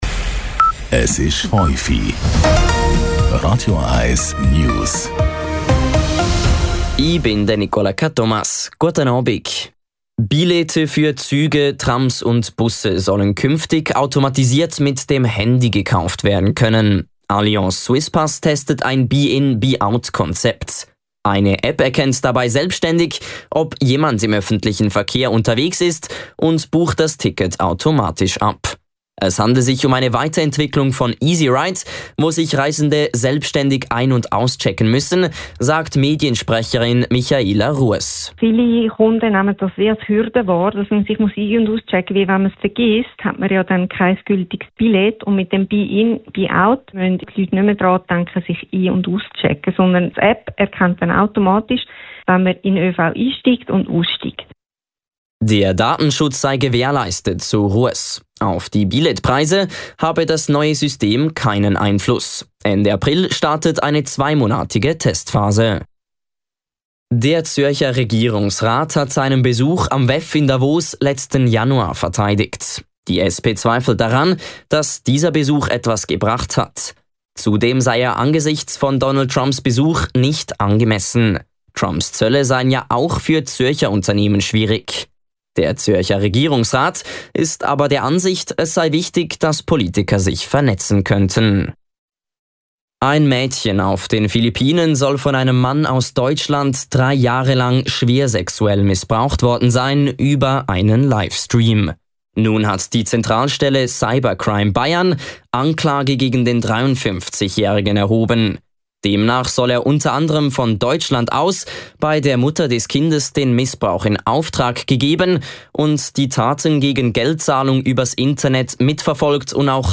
Die aktuellsten News von Radio 1 - kompakt, aktuell und auf den Punkt gebracht.